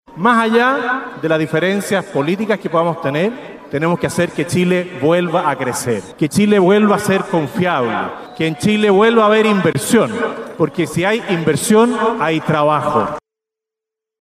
En medio de este contexto, el propio Presidente Kast hizo un llamado a la unidad durante su participación en la septuagésimo séptima versión del Campeonato Nacional de Rodeo, en Rancagua, donde enfatizó la necesidad de retomar el crecimiento económico del país.